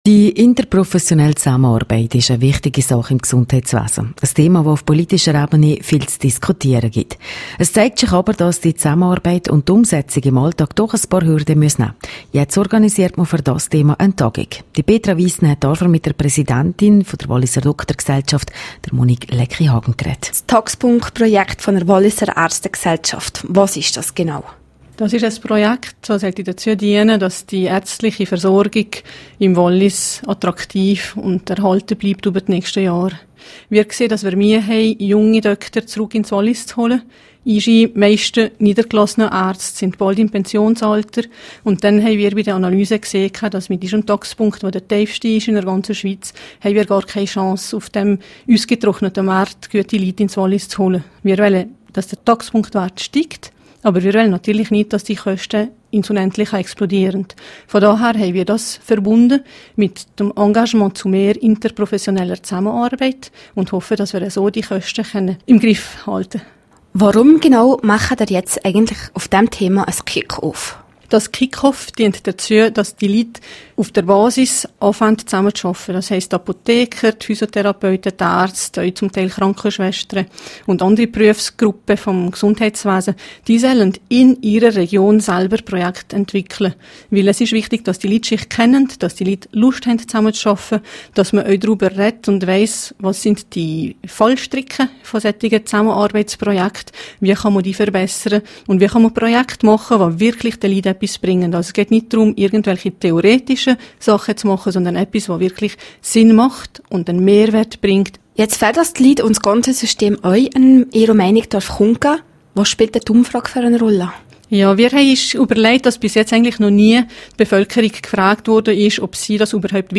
16158_News.mp3